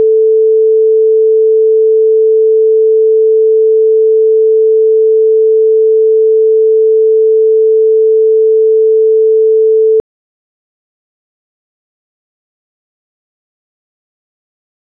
pulses.wav